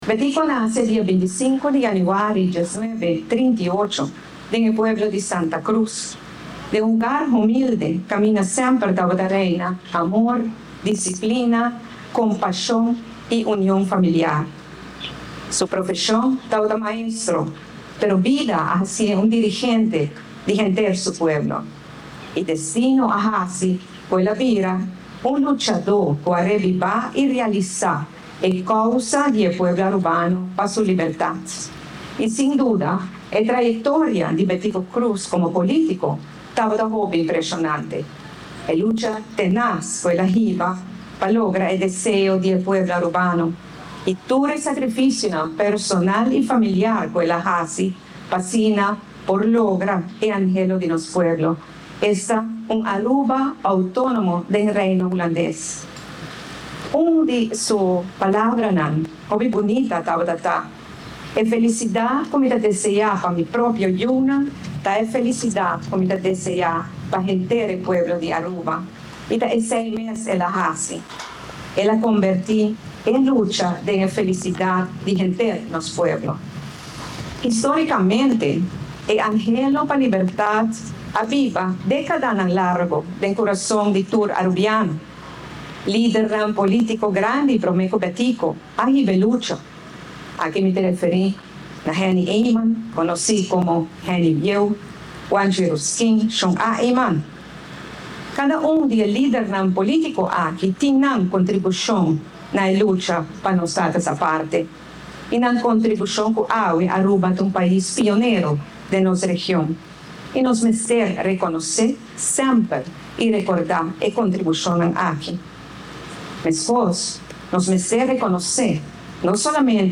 Na e ocasion, Prome Minister Evelyn Wever-Croes a duna un discurso comparando e lucha di Betico pa nos Status Aparte cu e construccion di e cas cu yama pais Aruba.